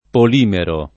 polimero [ pol & mero ]